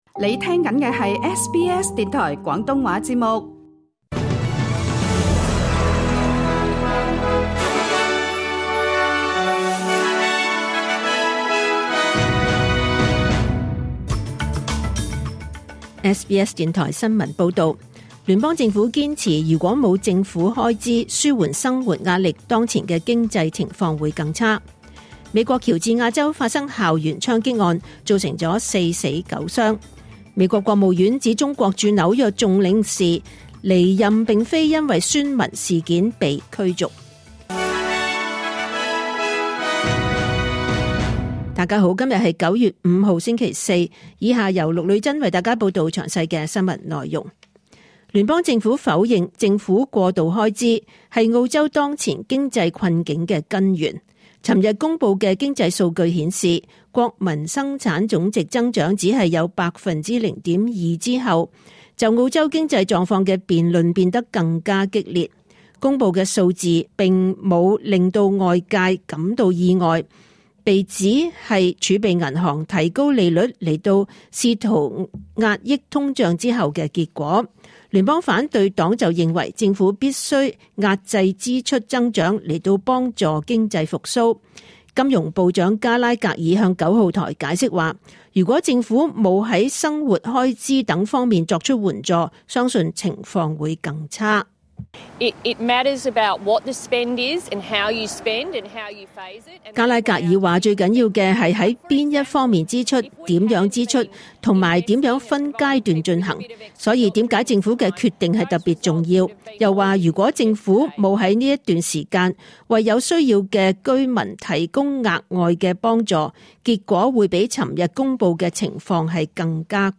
2024 年 9 月 5 日 SBS 廣東話節目詳盡早晨新聞報道。